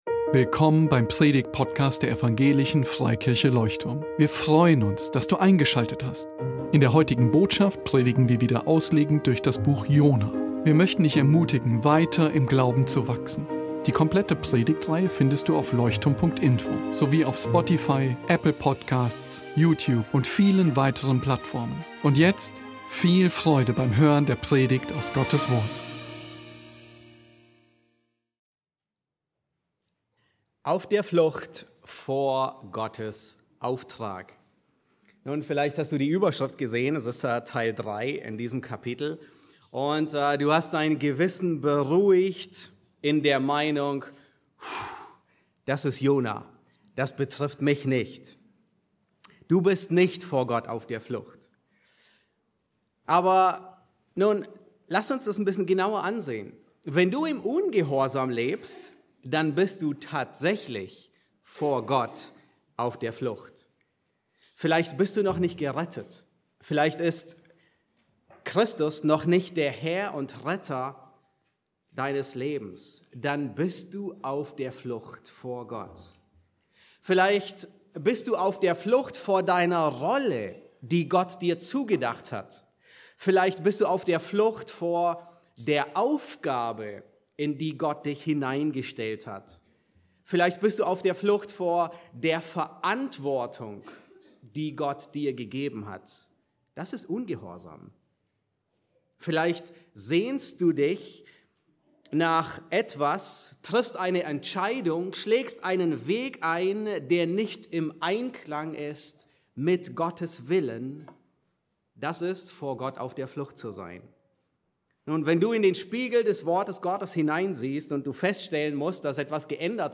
Leuchtturm Predigtpodcast